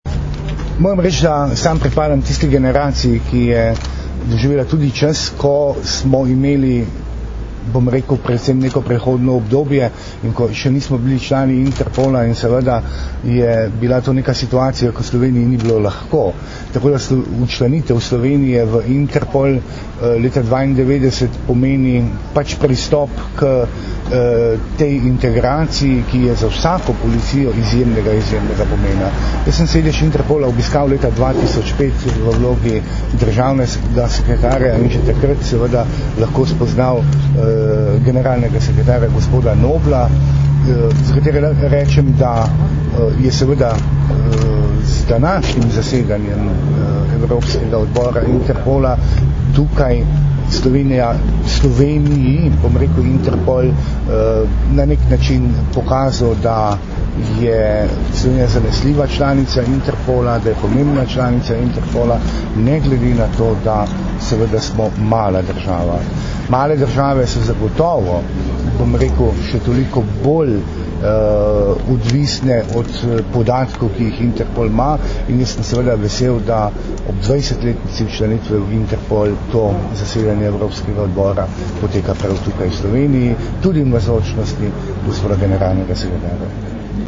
Zvočni posnetek izjave za medije dr. Vinka Gorenaka (mp3)